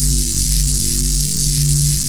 lightningloop02.wav